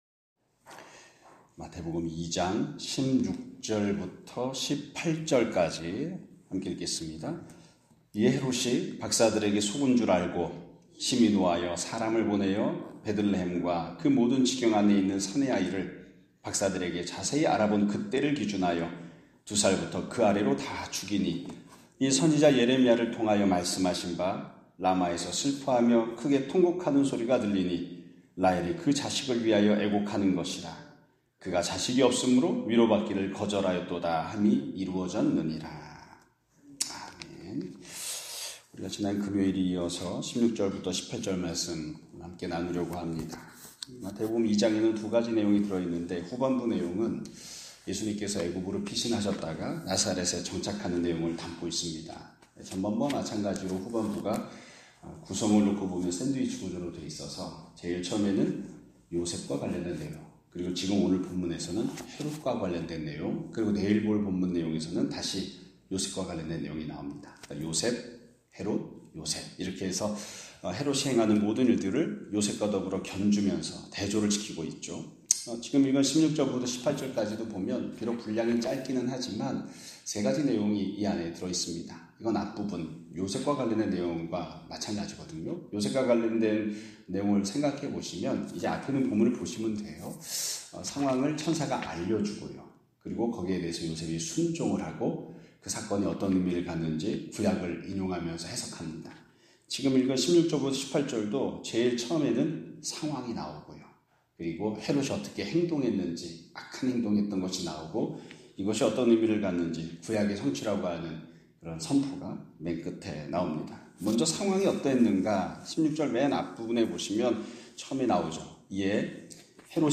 2025년 4월 7일(월 요일) <아침예배> 설교입니다.